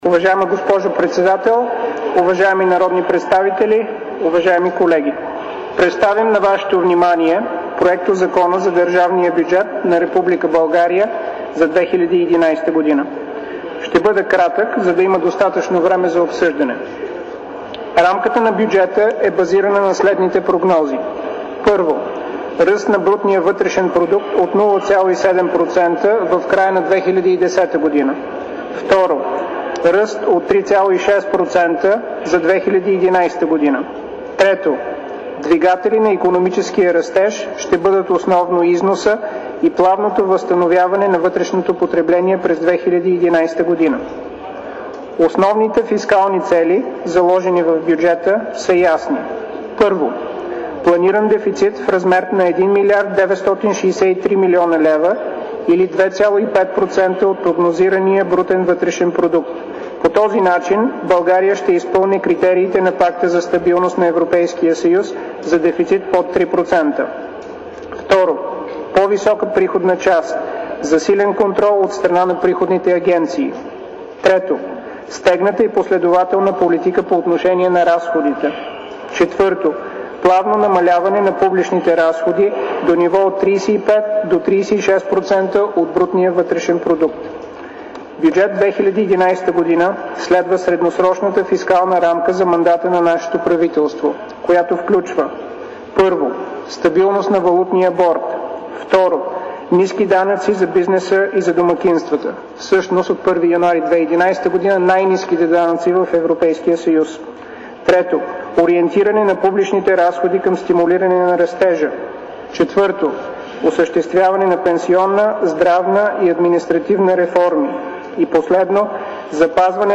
Представянето на бюджет 2011 от Симеон Дянков в НС при първото четене на законопроекта